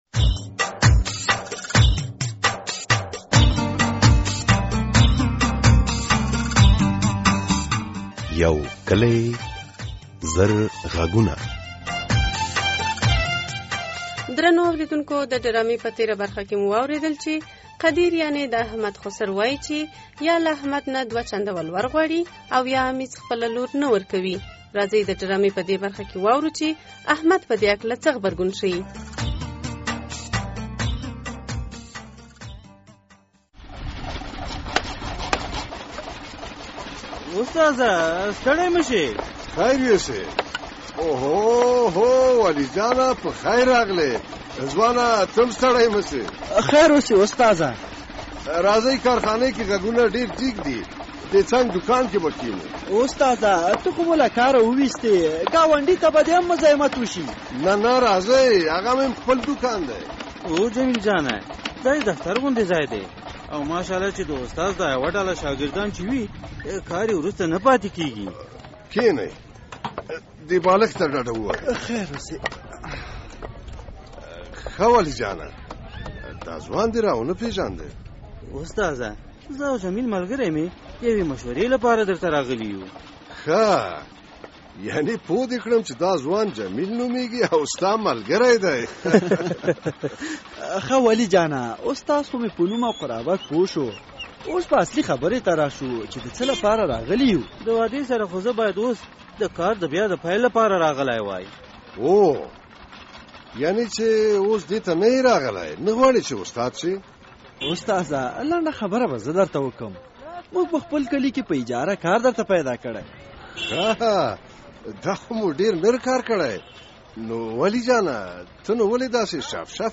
د جمعې ورځې په سبا ژوندی خپرونه کې په دې غږیږو چې د مشرانو له هڅو او د دیني عالمانو له وعظ سره خپله ځوانان څنګه کولای شي چې دلوړ ولور مخه ونیسي؟